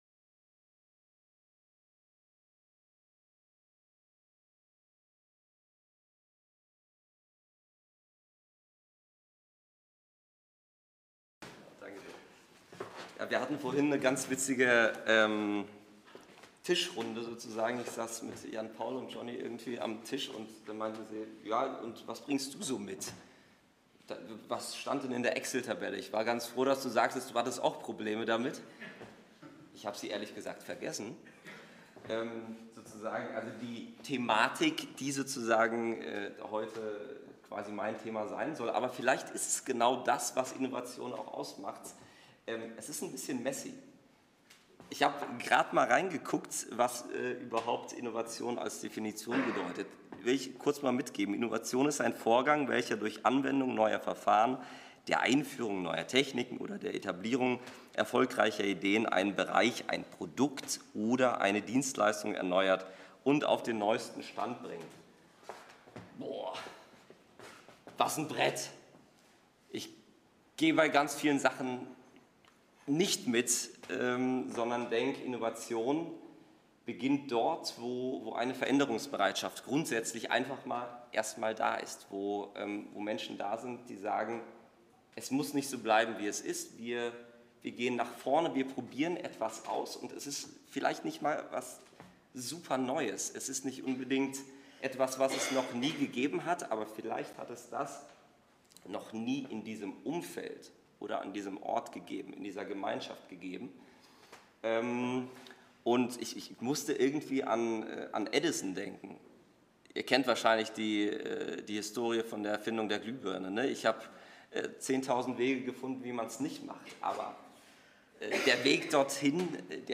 Themenbereich: Vortrag